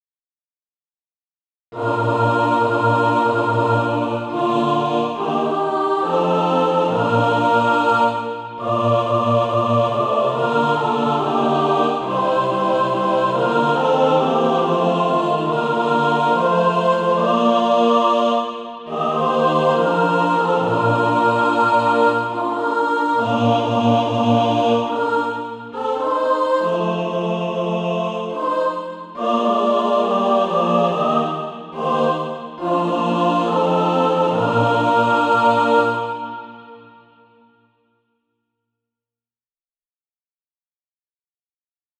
(SATB) Author
And also a mixed track to practice to
Practice then with the Chord quietly in the background.